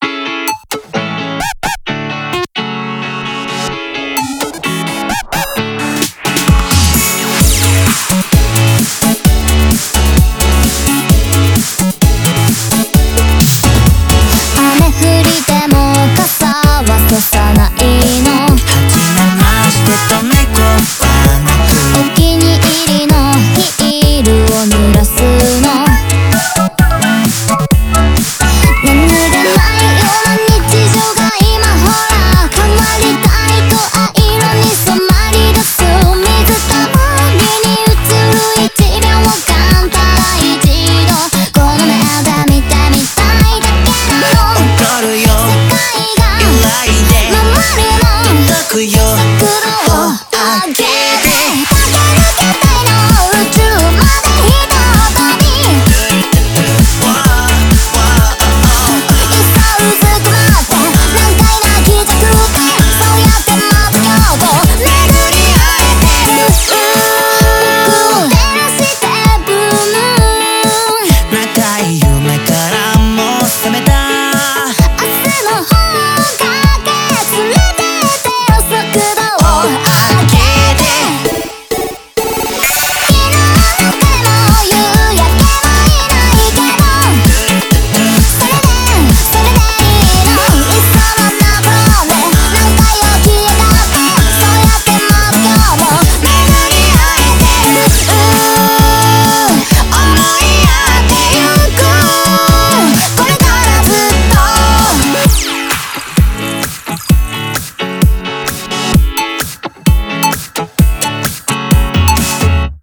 BPM130--1
Audio QualityPerfect (High Quality)